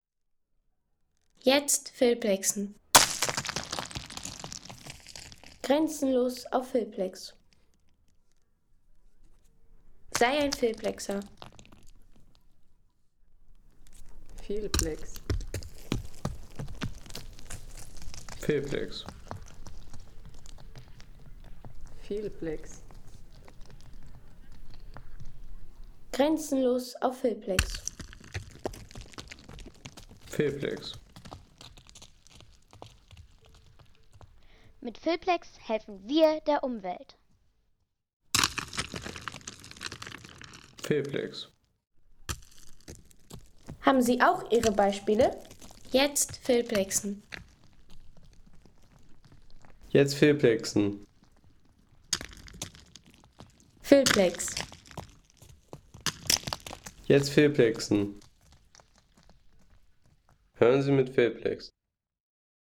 Klang der Dolomitsteine